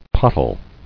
[pot·tle]